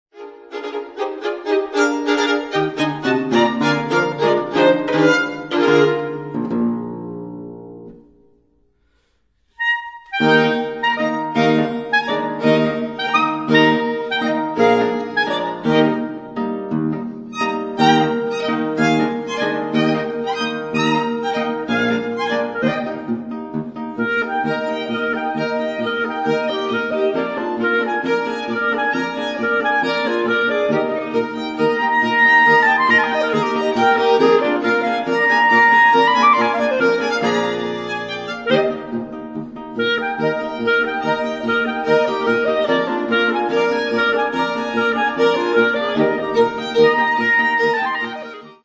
* Quartett mit G-Klarinette
Steinerner Saal, Musikverein Wien